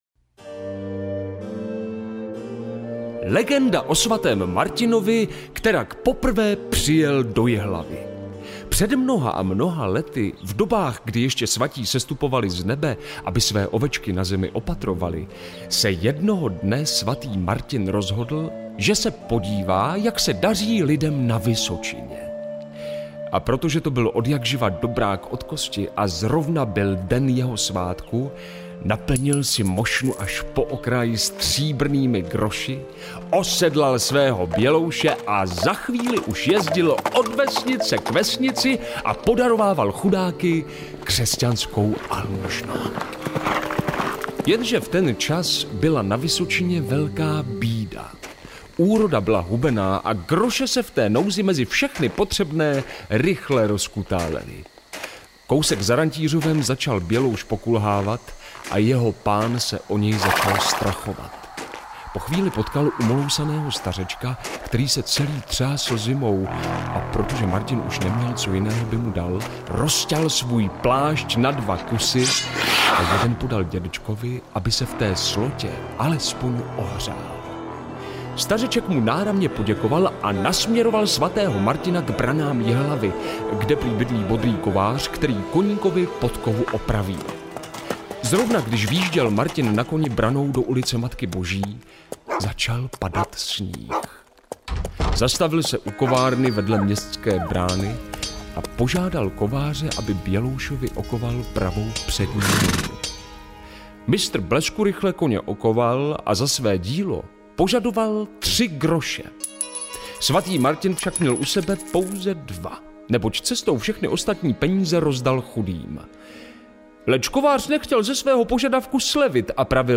nazpíval prastarý kánon.